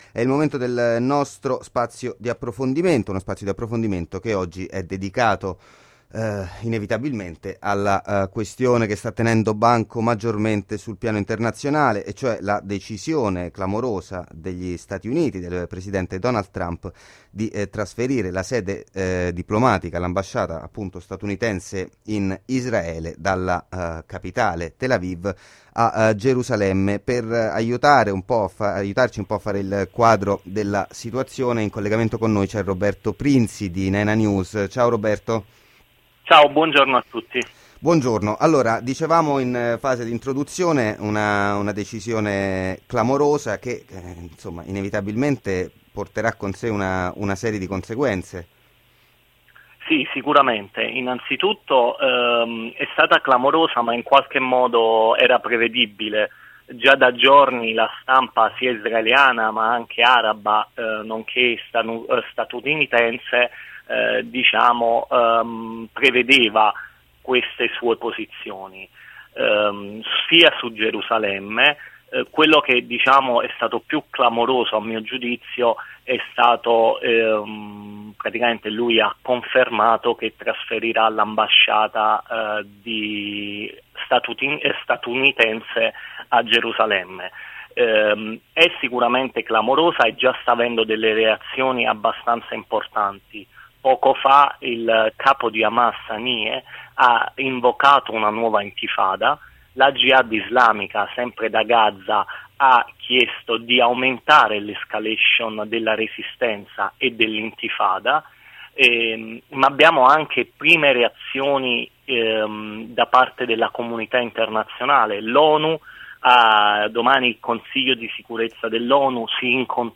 Gli Stati Uniti riconoscono Gerusalemme capitale dello Stato di Israele: quali conseguenze in Palestina e nella regione? Intervista